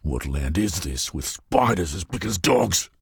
B_spiders_Dogs.ogg